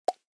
aimlab击中音效3.mp3